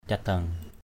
/ca-d̪aŋ/ 1.